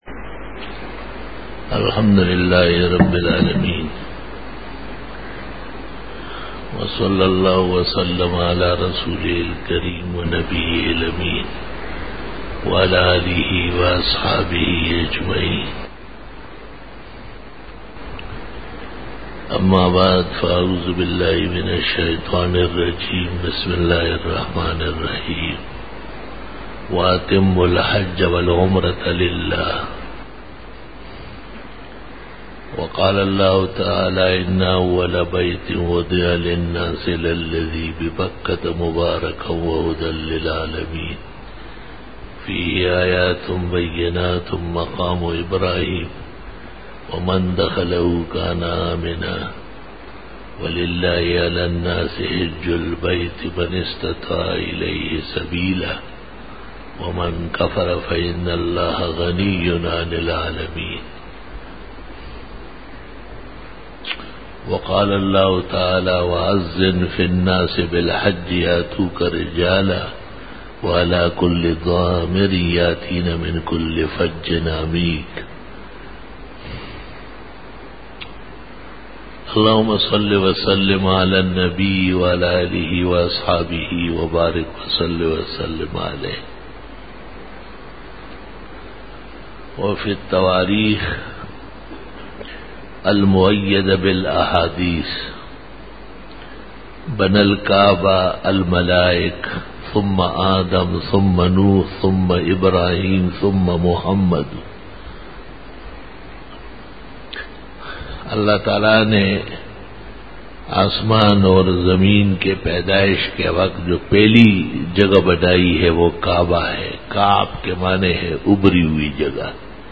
Khitab-e-Jummah 2013